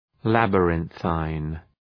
Shkrimi fonetik {,læbə’rınɵın}
labyrinthine.mp3